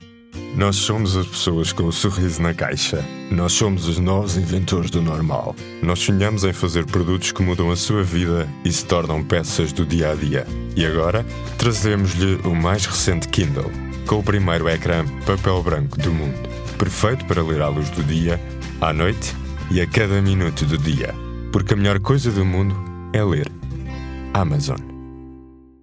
Portuguese, Male, 30s-40s